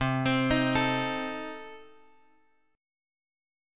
C Minor 6th